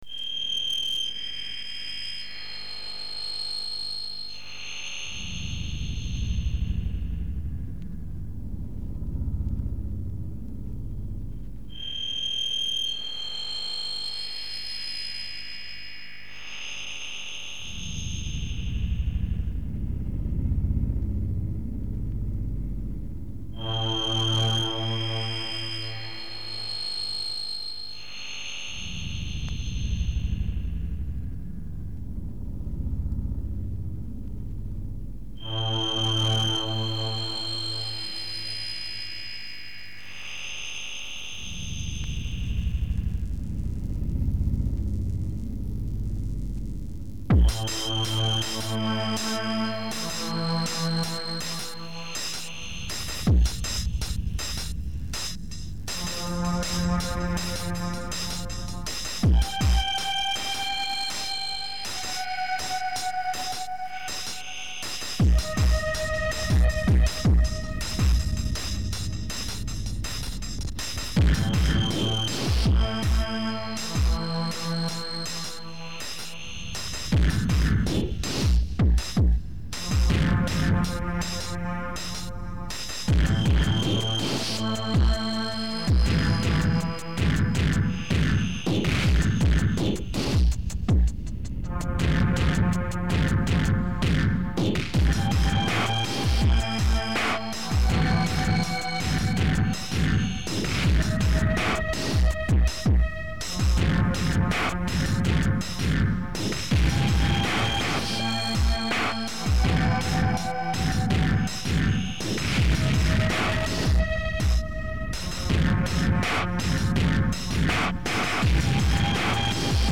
who are some good dark dnb djs?